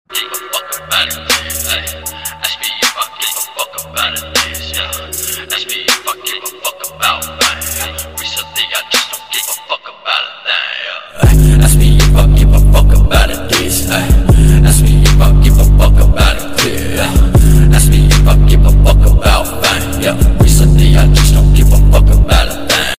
Rx 7 engine bay (4 Rotor) sound effects free download
Rx-7 engine bay (4 Rotor) Mp3 Sound Effect